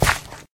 gravel1.mp3